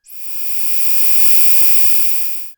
ihob/Assets/Extensions/RetroGamesSoundFX/Hum/Hum26.wav at master
Hum26.wav